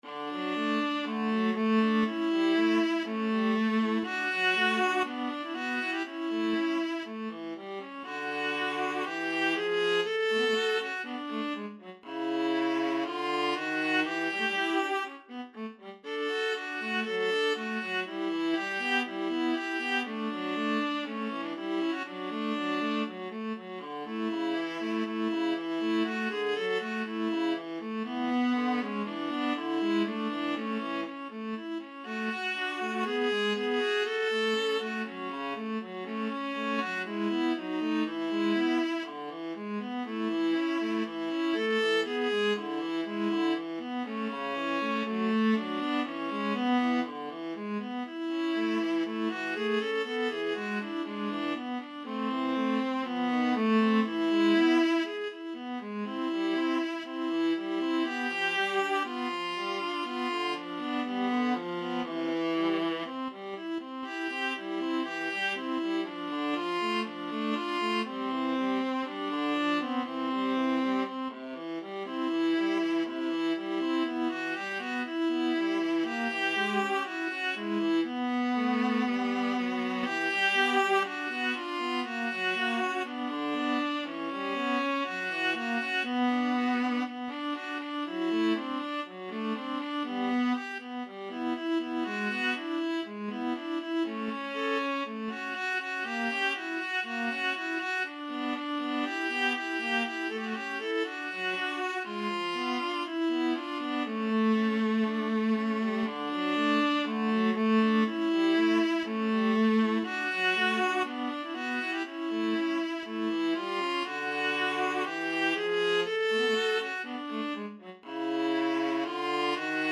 adagio Slow =c.60
2/4 (View more 2/4 Music)
Classical (View more Classical Viola Duet Music)